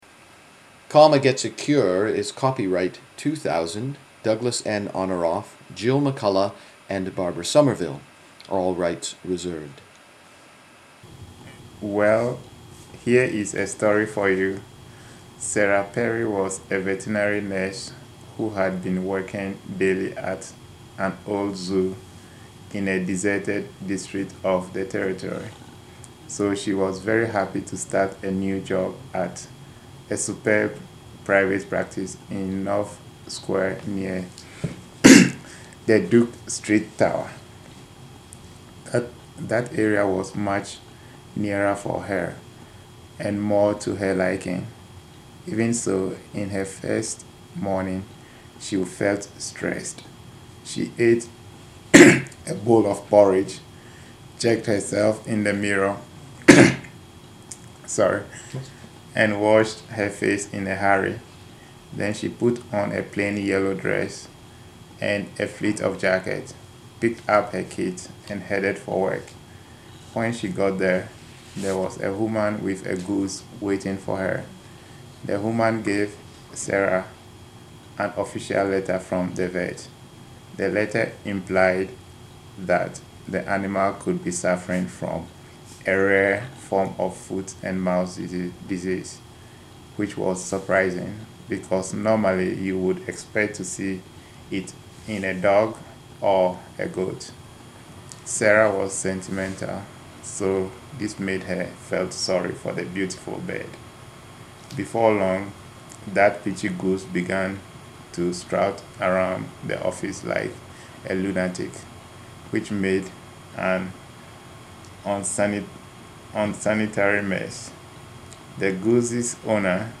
Ghana
Ghana :: Common Text
Ghana_Common_Text.mp3